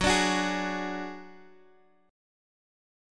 Gbsus2#5 chord